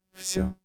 Роботизированная озвучка это будущее